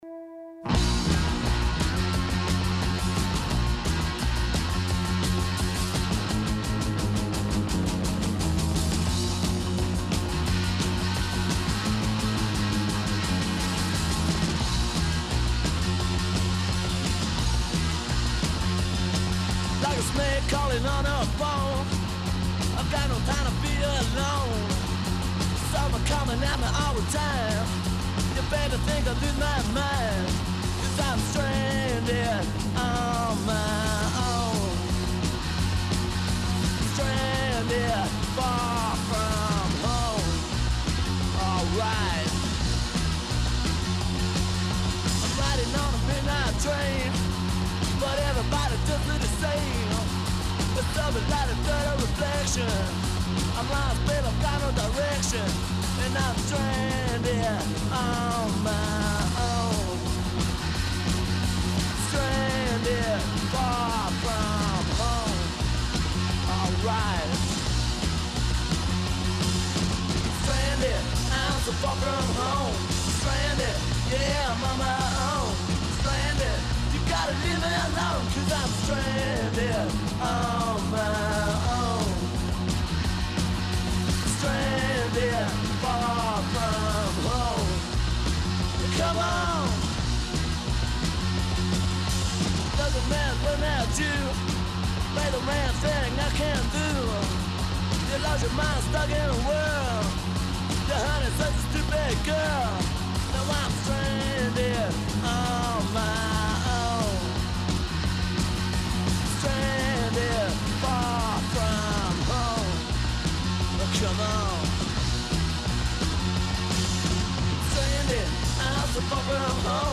Guest Interview w/ The Saints: Rātu October 21, 2025